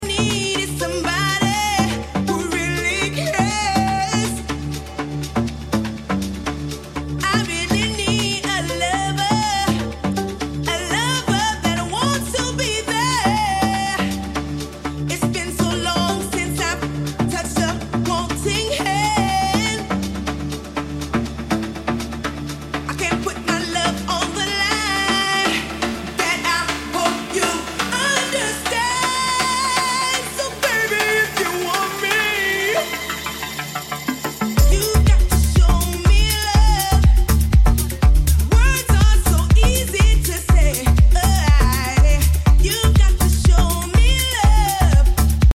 DJ set